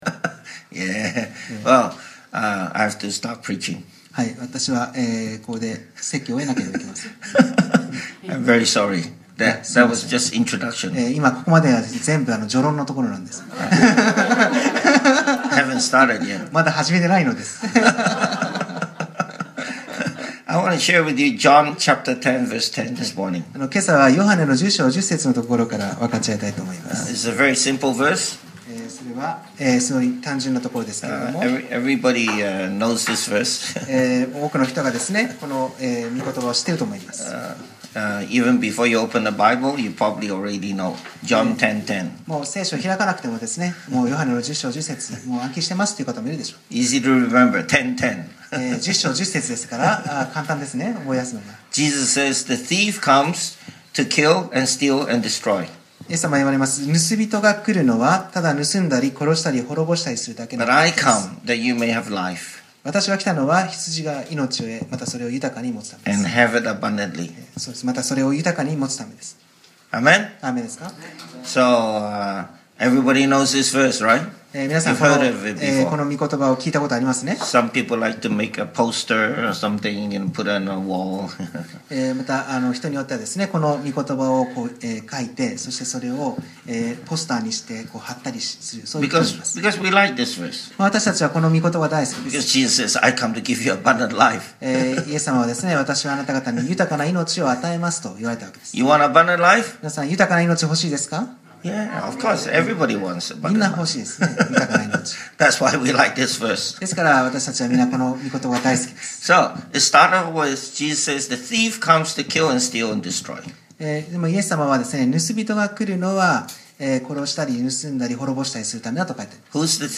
2015年5月10日（日）礼拝説教 『教会の７つの本質』